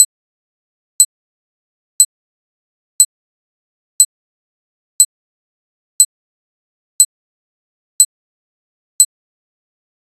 minibombcountdown.ogg